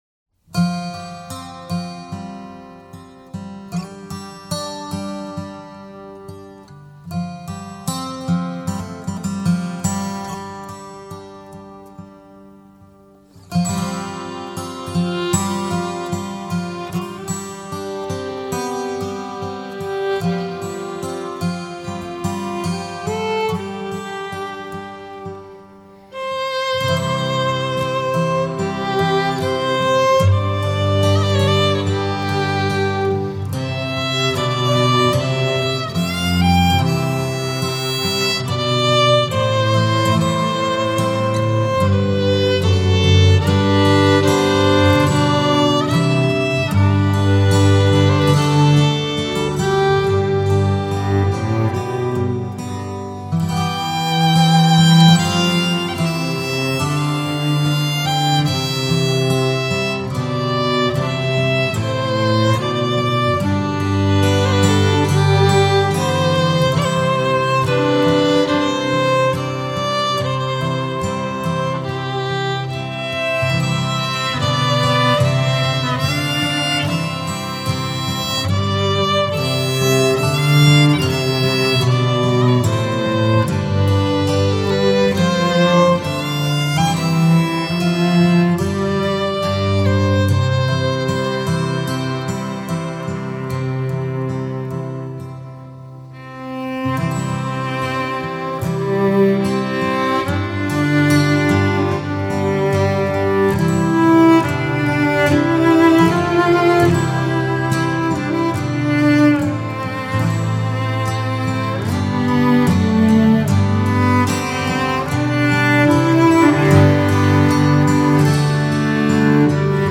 I had this on my computer but it cuts off at the end.